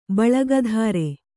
♪ baḷaga dhāre